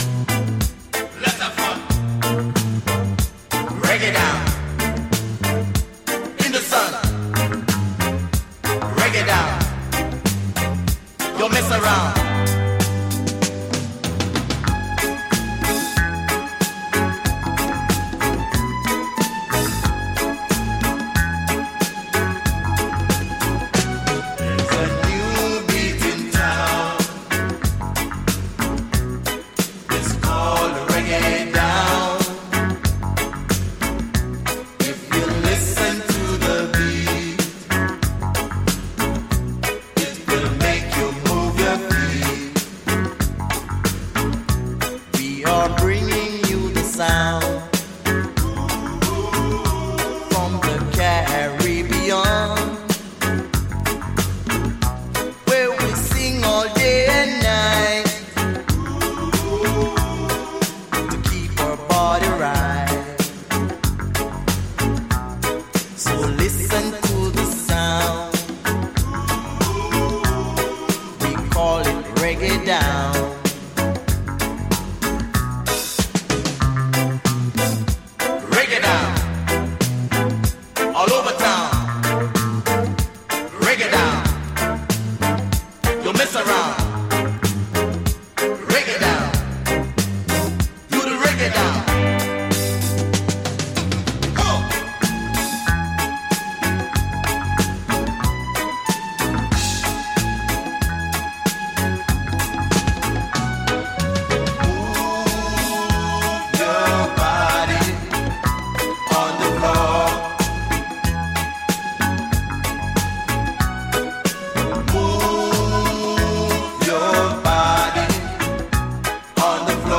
Caribbean funksters
does indeed feature a skank of sorts
Disco Dub Funk